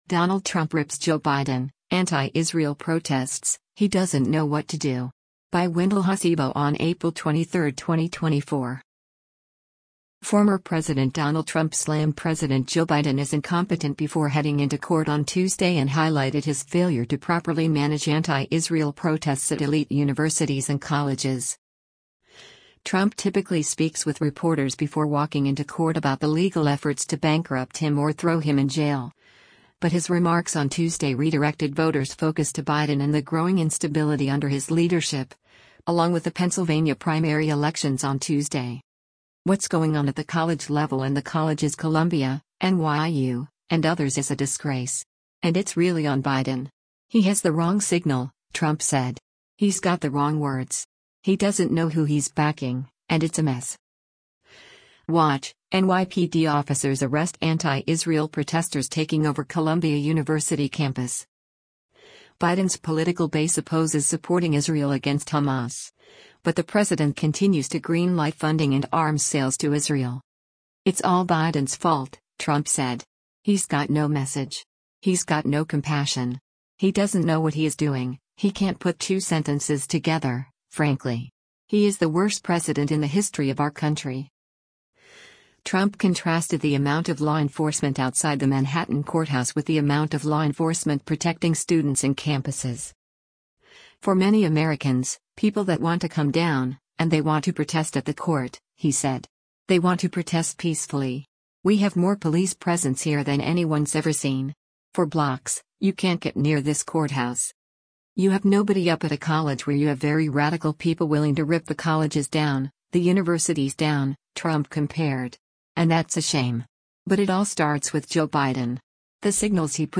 Trump typically speaks with reporters before walking into court about the legal efforts to bankrupt him or throw him in jail, but his remarks on Tuesday redirected voters’ focus to Biden and the growing instability under his leadership, along with the Pennsylvania primary elections on Tuesday.